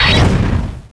pokeemerald / sound / direct_sound_samples / cries / larvesta.aif